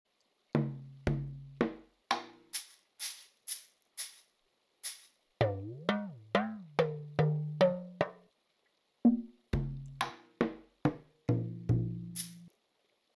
Basic_Drum_Sound.mp3